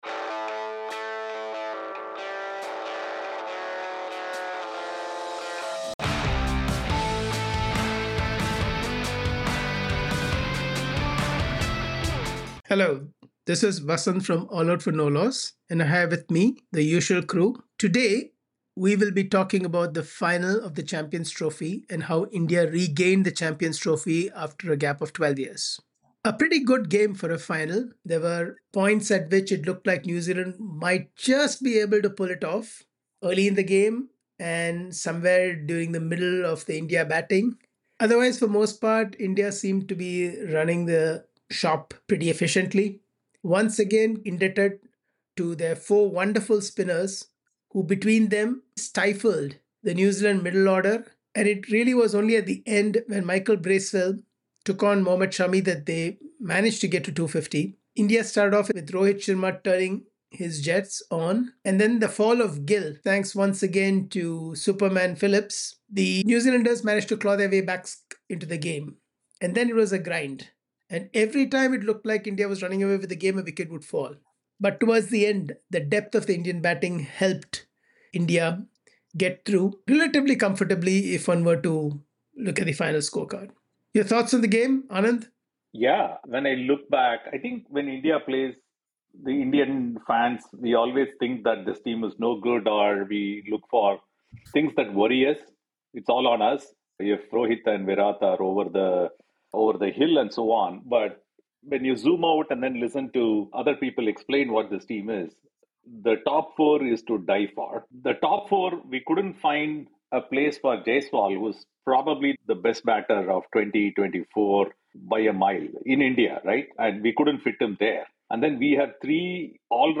In this conversation we will take a look at the game and this India team in historical terms